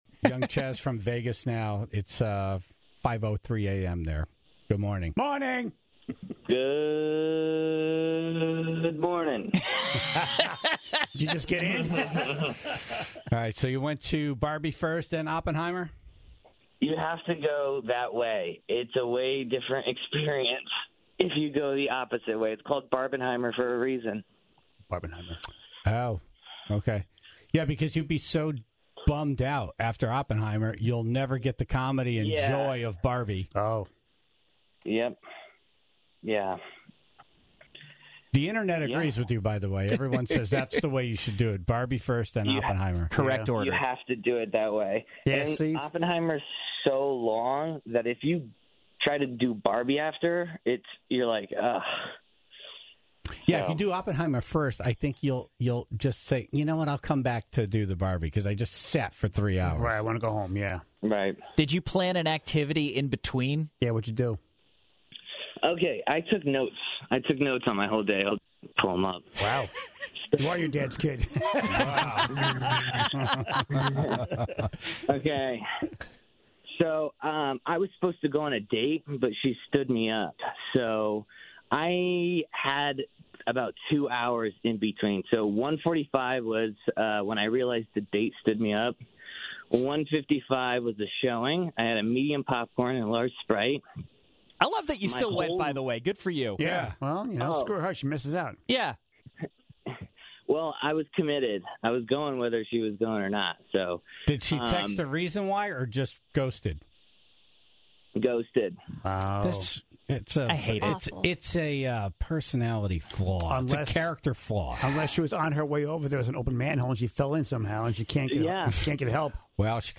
He was on the phone to share the whole experience, reviewing his notes, including the food he ate, bathroom breaks he needed, and how he was kicked out of...